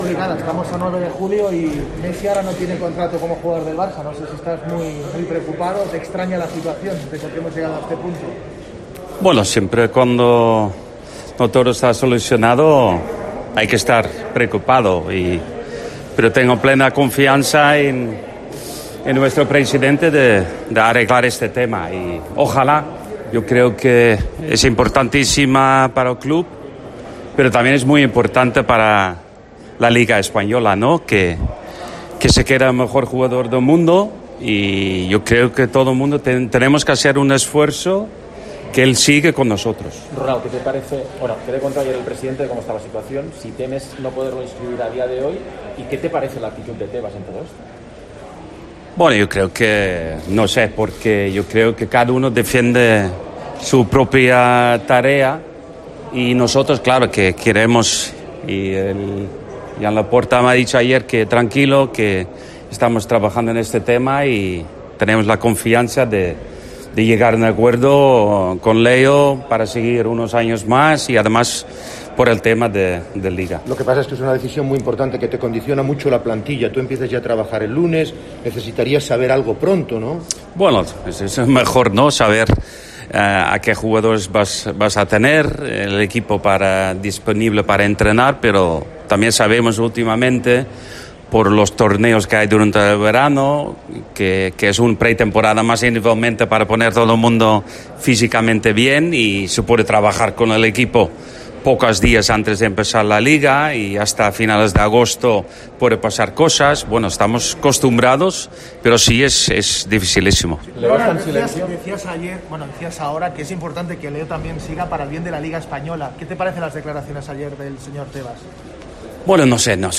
El entrenador del FC Barcelona Ronald Koeman ha hablado este viernes en el torneo benéfico de golf 'Koeman Cup' en el Club Golf Barcelona de Sant Esteve Ses Rovires, cercano a Barcelona.